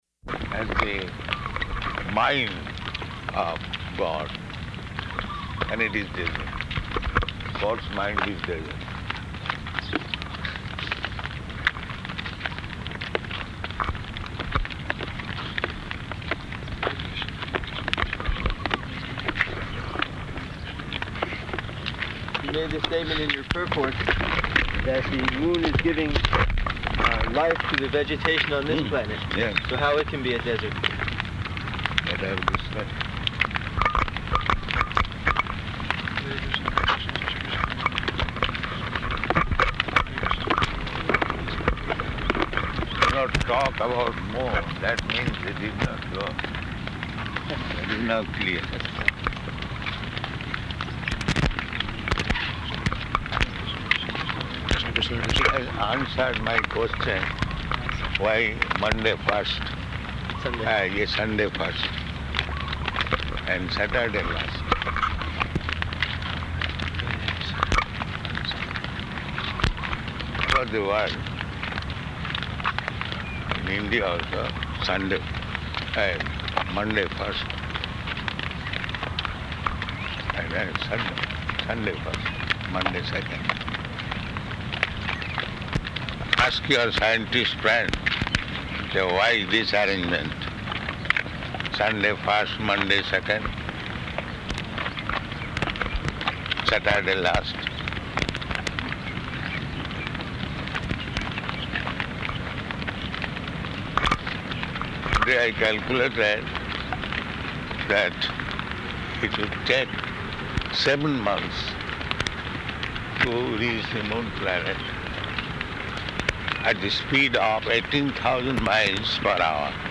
Morning Walk --:-- --:-- Type: Walk Dated: May 25th 1976 Location: Honolulu Audio file: 760525MW.HON.mp3 Prabhupāda: ...of the mind of God.